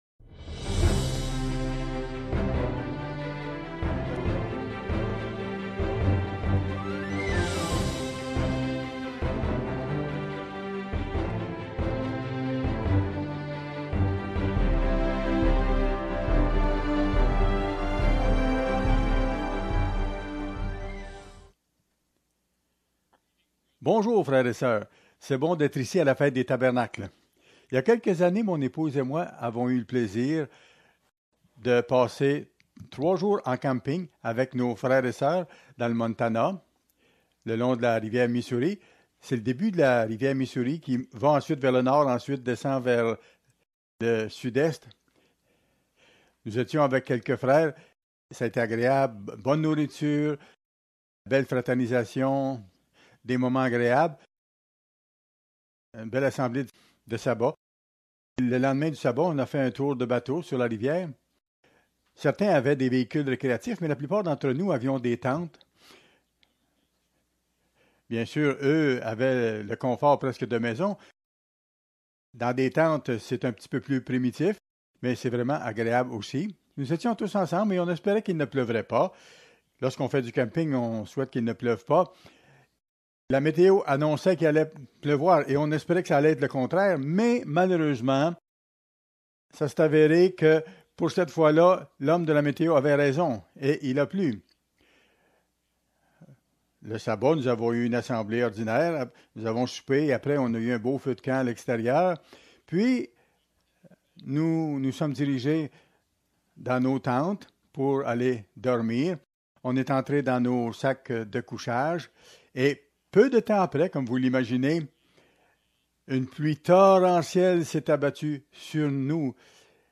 Fête des Tabernacles